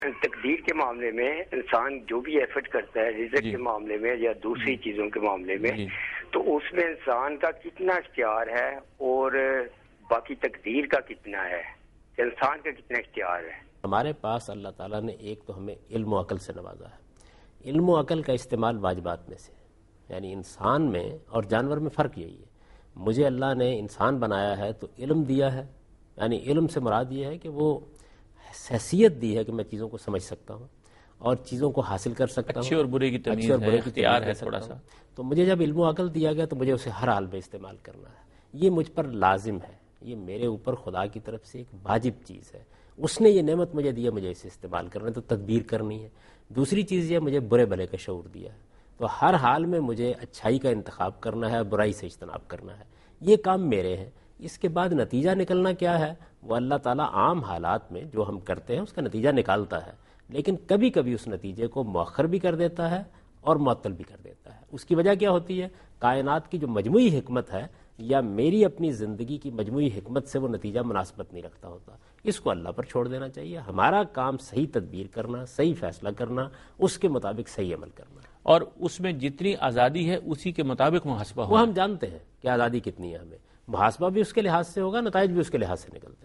Answer to a Question by Javed Ahmad Ghamidi during a talk show "Deen o Danish" on Duny News TV
دنیا نیوز کے پروگرام دین و دانش میں جاوید احمد غامدی ”انسانی کوشش اور تدبیر“ سے متعلق ایک سوال کا جواب دے رہے ہیں